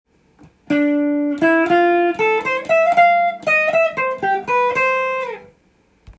譜面通り弾いてみました
④のフレーズは、主にコードトーンとメジャースケールを用いた
音使いになっています。